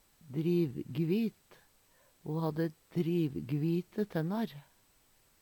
drivgvit - Numedalsmål (en-US)